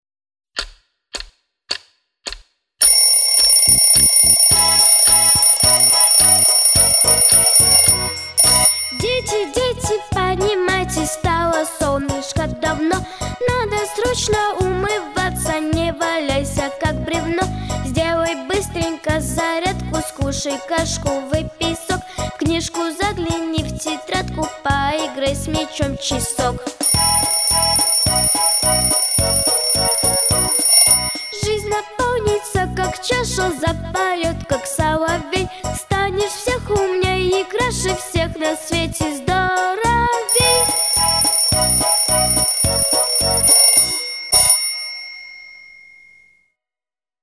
III Театральный Фестиваль начальной школы